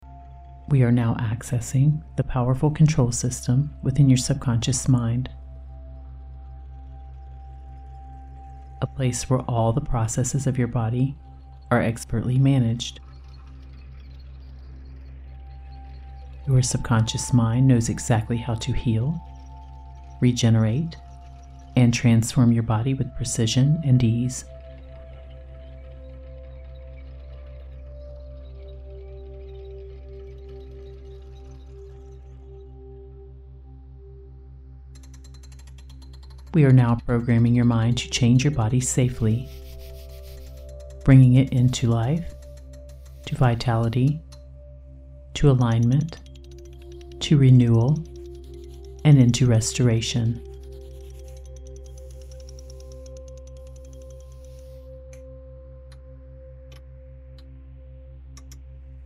Powerful Healing and Total Body Transformation Guided Hypnosis (Extended Version)
Harnessing advanced hypnotic techniques, theta wave entrainment, and 3D sound design, this extended session immerses you in a futuristic healing chamber, where your subconscious mind becomes the architect of your perfect transformation.
The pacing of this session has been carefully adjusted to allow deeper absorption of the hypnotic suggestions, ensuring lasting and profound changes at the subconscious level.
Experience the power of 3D sound design with binaural beats and ASMR-style effects, designed to deepen your relaxation and enhance the hypnotic impact on your subconscious.
The embedded theta wave track entrains your brain to enter a deep meditative state, making your subconscious more receptive to the healing and transformative suggestions.